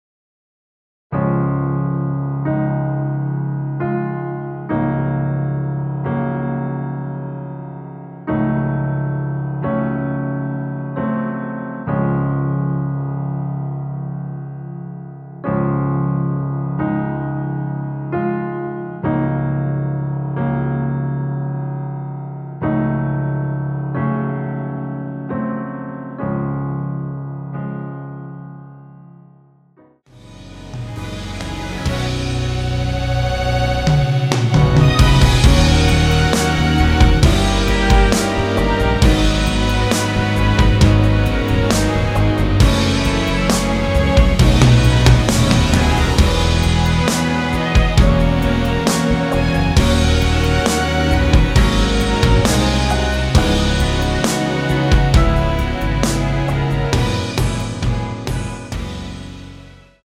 원키에서(-2)내린 MR 입니다.
Db
앞부분30초, 뒷부분30초씩 편집해서 올려 드리고 있습니다.
중간에 음이 끈어지고 다시 나오는 이유는